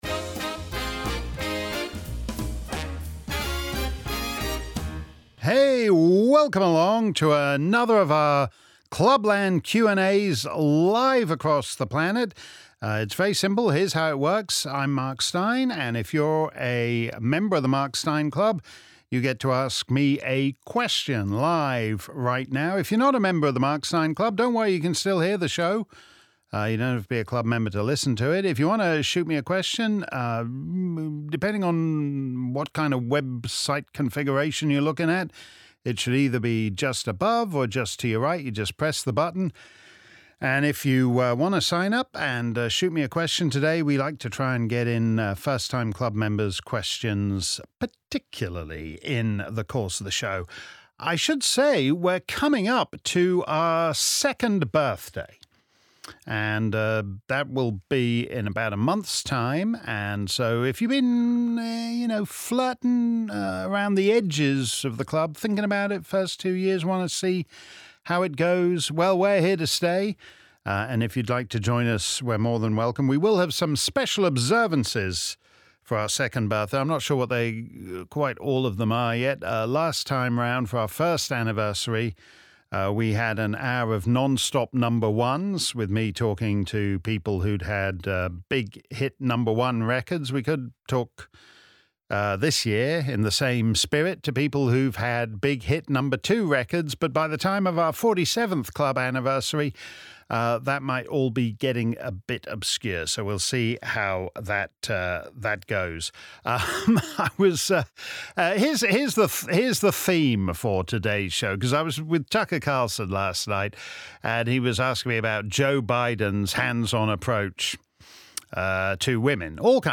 If you missed our livestream Clubland Q&A, here's the action replay.